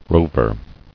[rov·er]